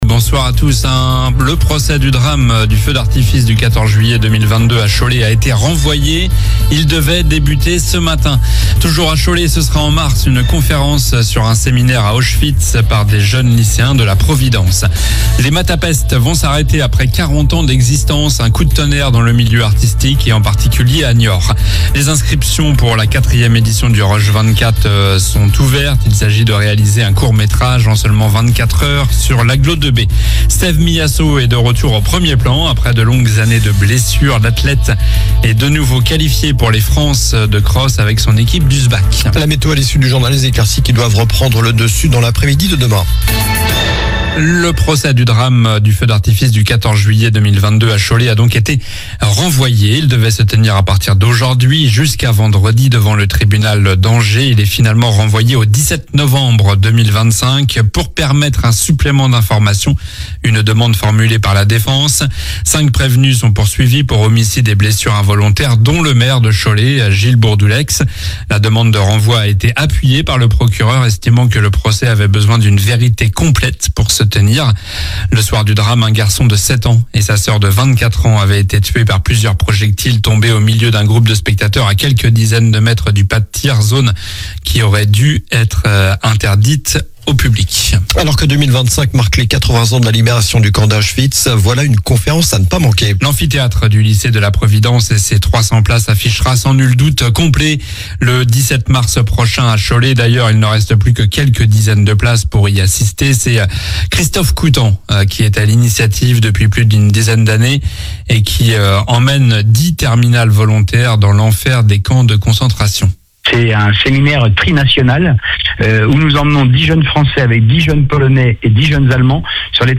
Journal du lundi 24 février (soir)